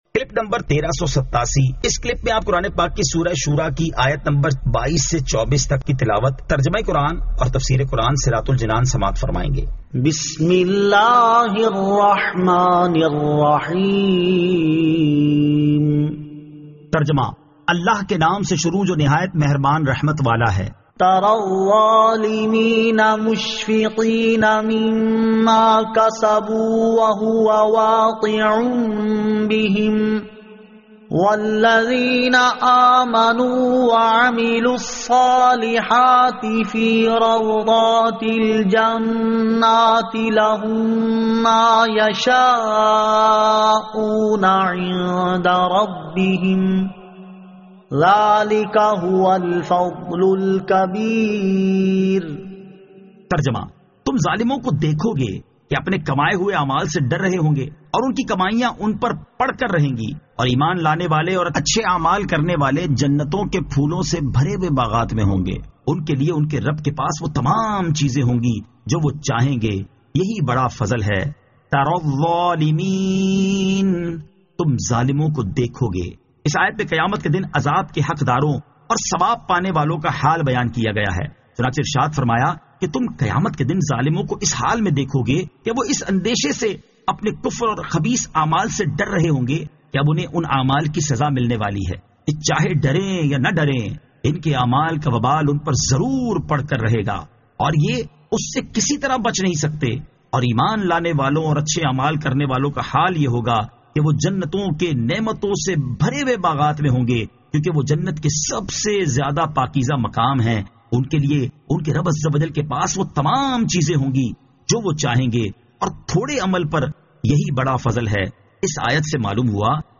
Surah Ash-Shuraa 22 To 24 Tilawat , Tarjama , Tafseer